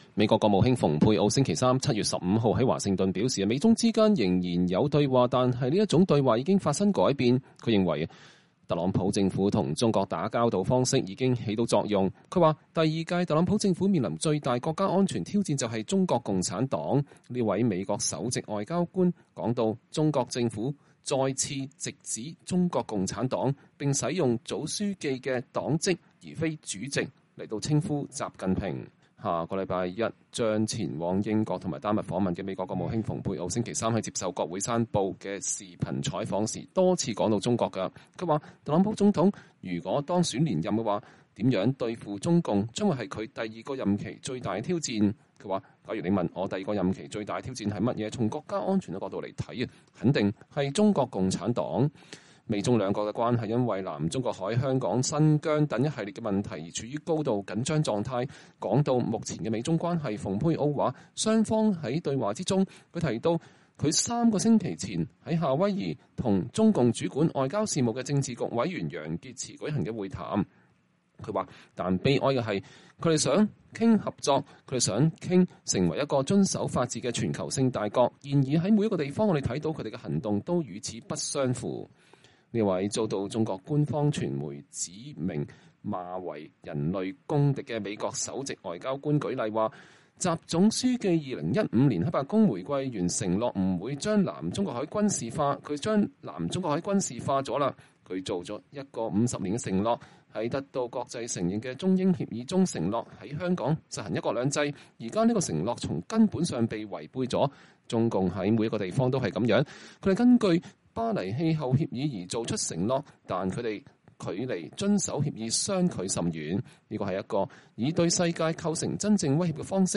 美國國務卿蓬佩奧在國務院舉行的記者會上講話。